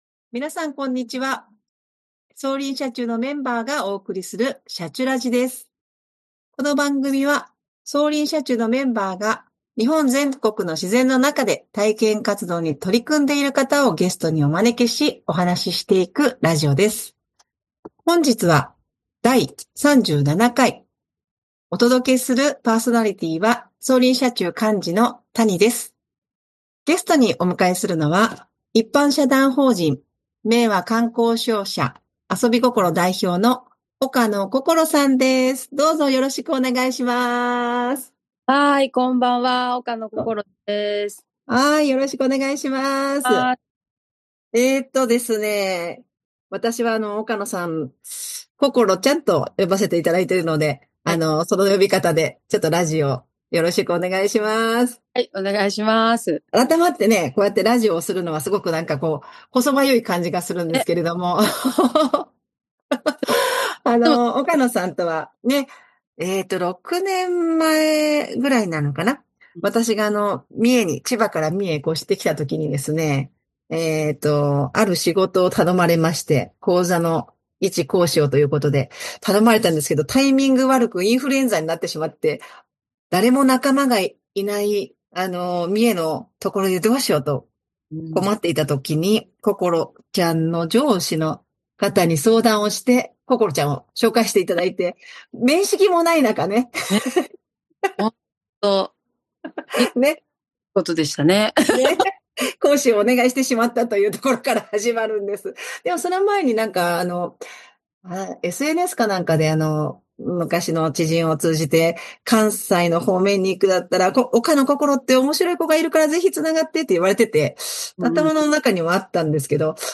【今回のゲストスピーカー】